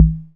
BDRUM1.WAV